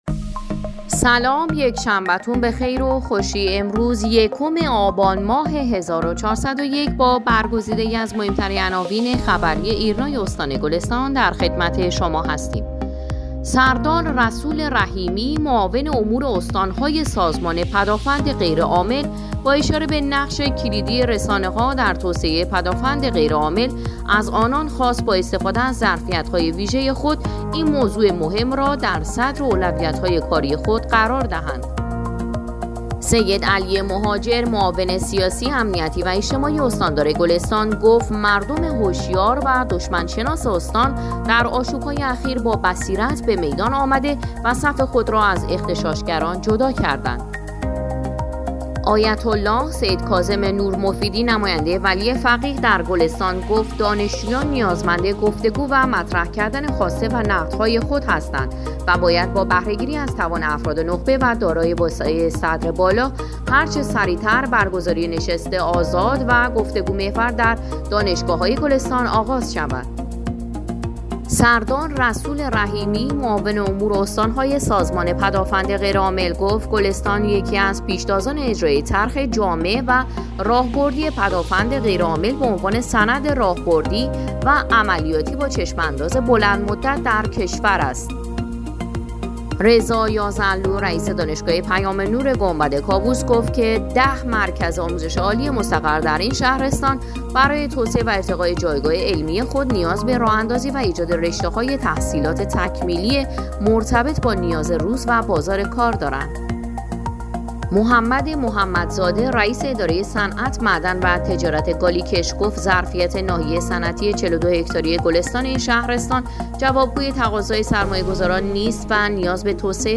صوت/ اخبار شبانگاهی اول آبان ایرنا گلستان